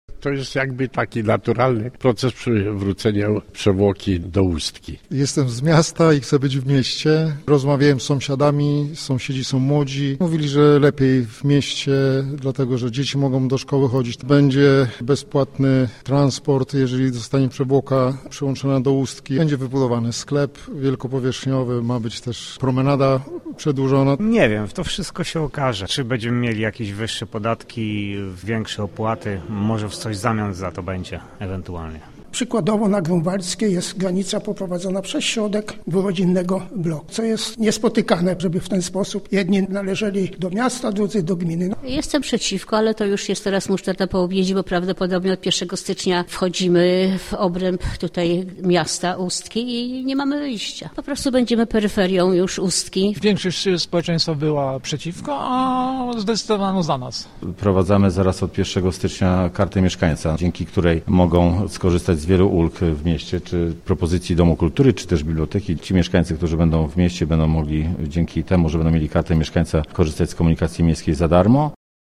Władze Ustki zorganizowały spotkanie w ratuszu, na którym przekonywały o korzyściach płynących ze zmiany adresu.